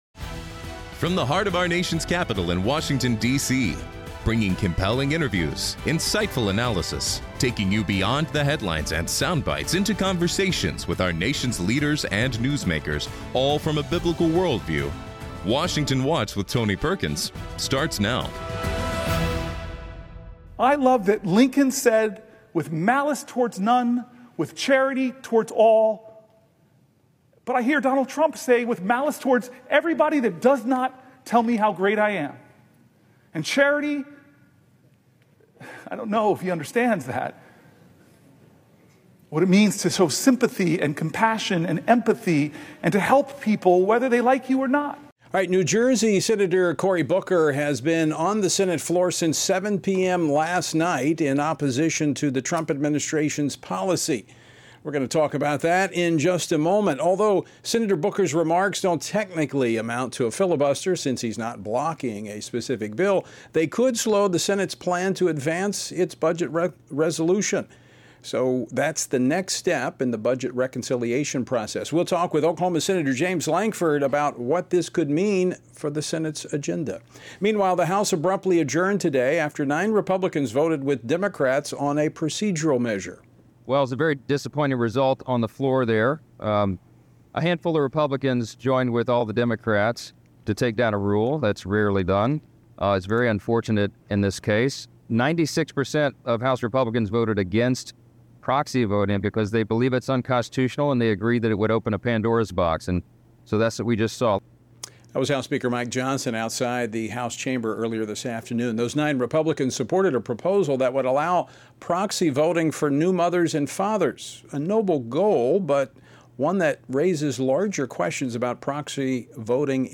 Nathaniel Moran, U.S. Representative for Texas’s 1st District, offers insight on the House fight over proxy voting. John Cornyn, U.S. Senator from Texas, shares breaking news about illegal border crossings, as well as President Trump’s actions to deport violent gang members. Chuck Grassley, U.S. Senator from Iowa, describes his legislation to stop judicial overreach in the lower courts.